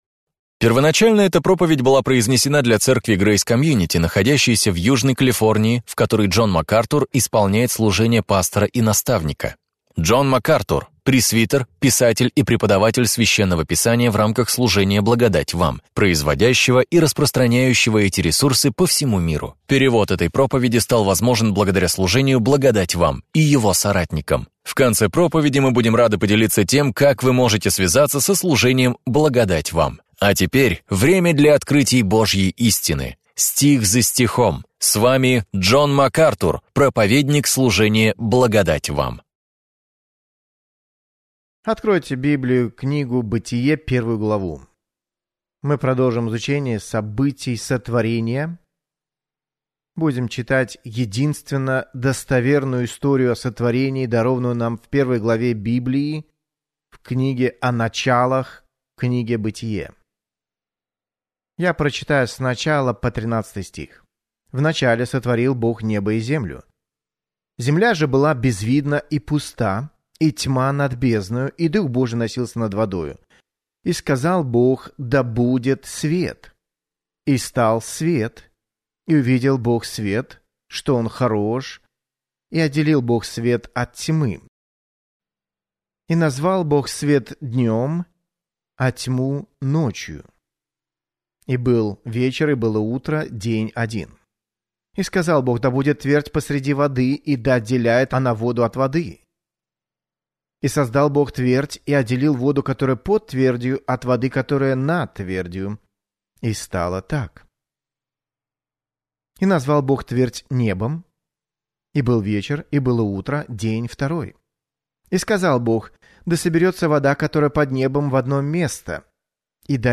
В своей проповеди «Битва за начало» Джон Макартур раскрывает суть этих споров